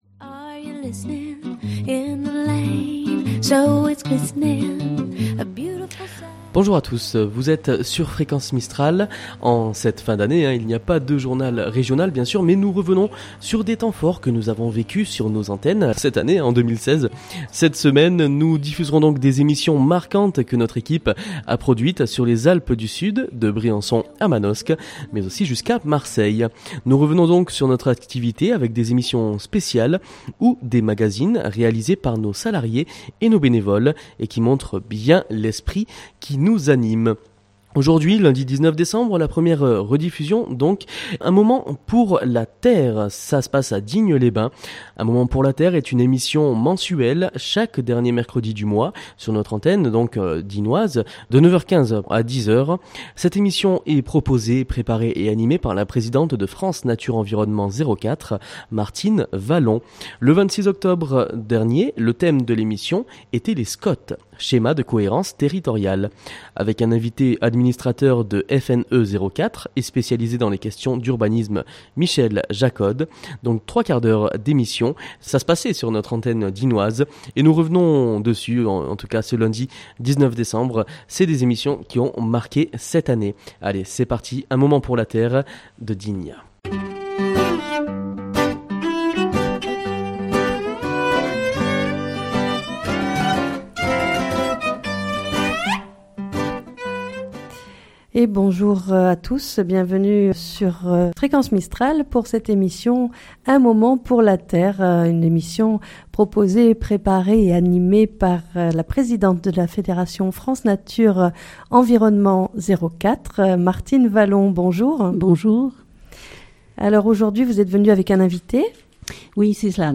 Pendant cette période de fêtes Fréquence Mistral vous propose des rediffusions d'émissions qui auront marqué l'année qui s'achève. Nous irons au gré de nos programmes sur nos antennes écouter quelques temps forts de l'année 2016.